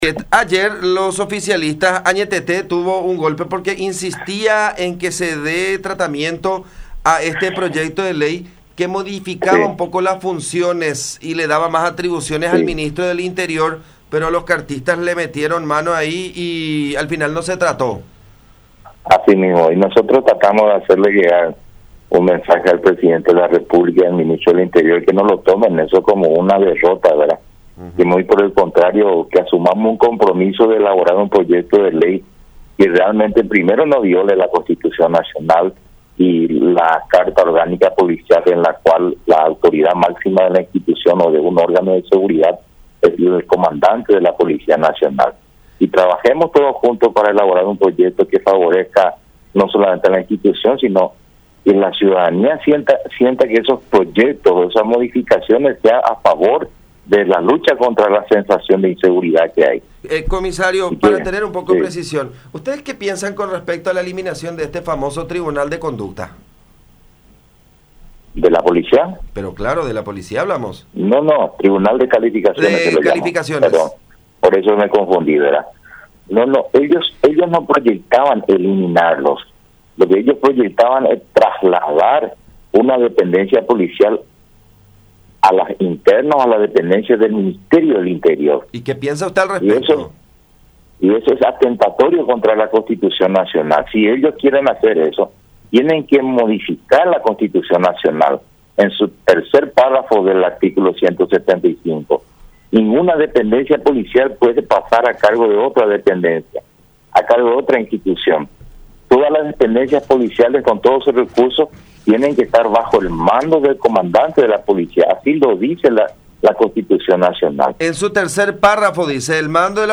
“Se debe trabajar por un proyecto que favorezca a la lucha contra la inseguridad, que sea el Comandante de la Policía Nacional el que tenga todas las atribuciones y prerrogativas”, subrayó el comisario retirado en contacto con La Unión.